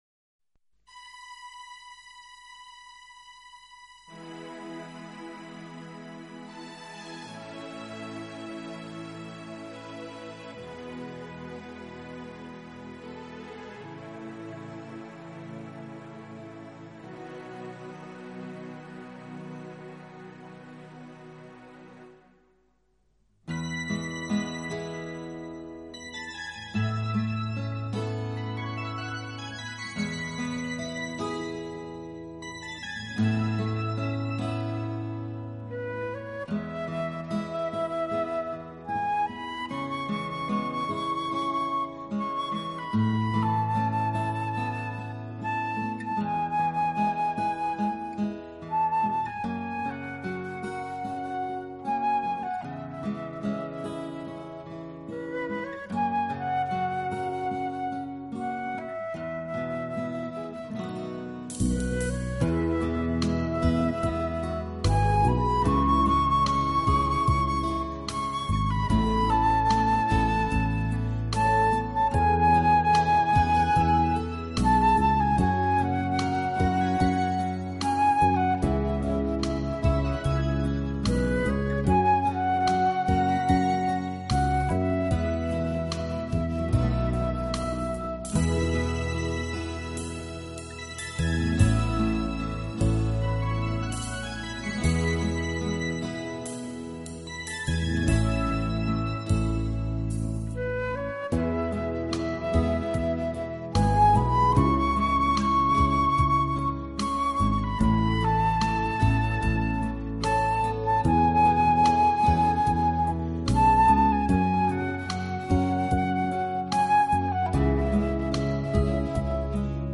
Acoustic（原音）是指原声乐器弹出的自然琴声（原音），制作录音绝对不含味精，乐器
其低音区较弱,音色柔和，高音区明亮而有穿透力，用于管弦乐队、军队，也用于独奏。